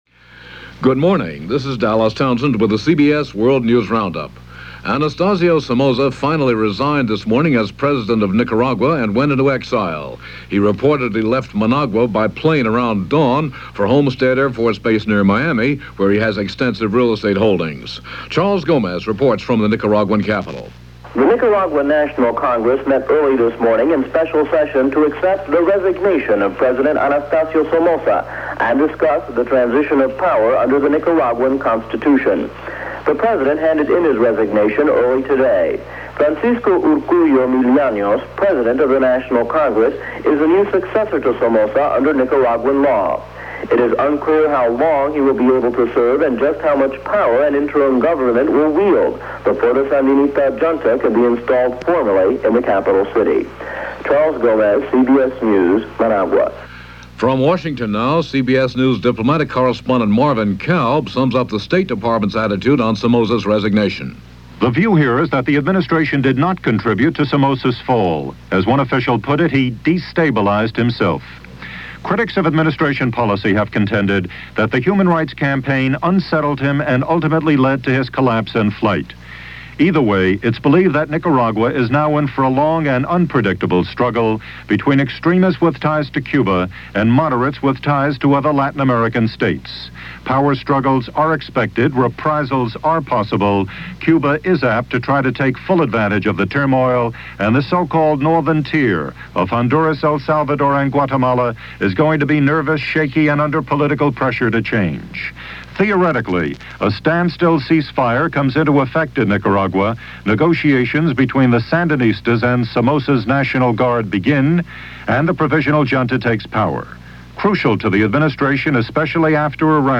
CBS World News Roundup